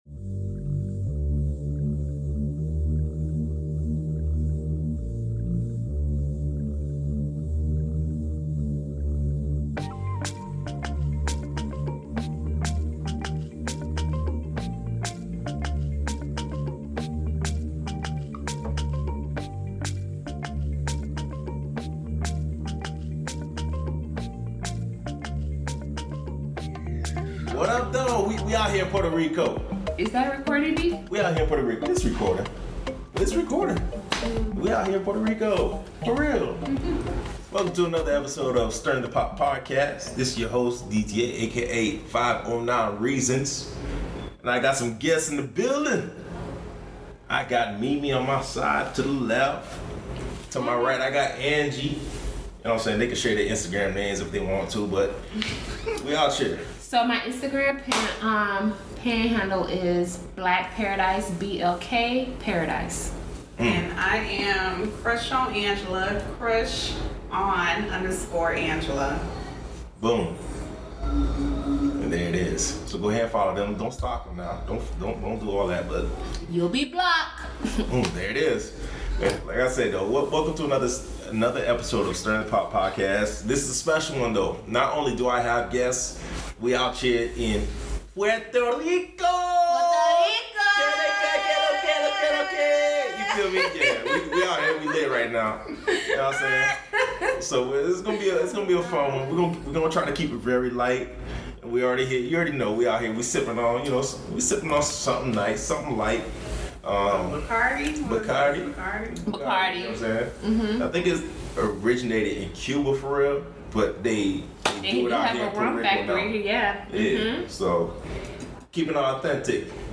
Live and direct from Puerto Rico